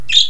Budgies are often talkative but not loud
(Please follow this link to hear Jasmine chirp), curious, friendly, inquisitive, and very active.
budgie.wav